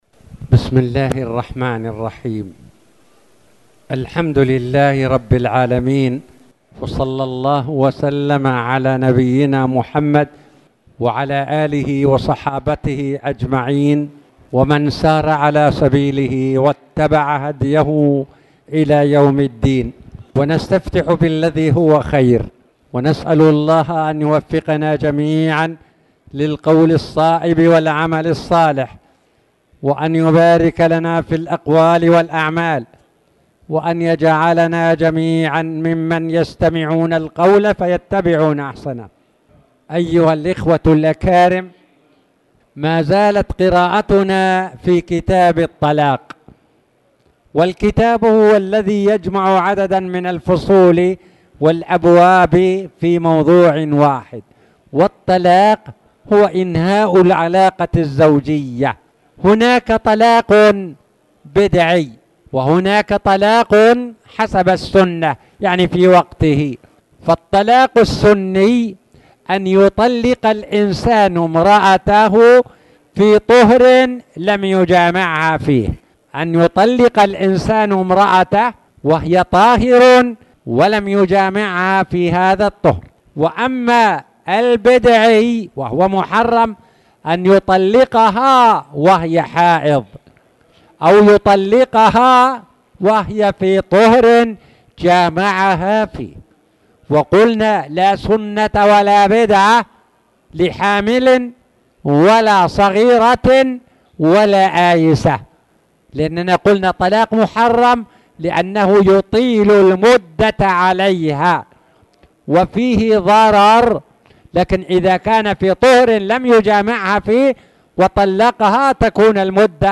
تاريخ النشر ٢٢ جمادى الآخرة ١٤٣٨ هـ المكان: المسجد الحرام الشيخ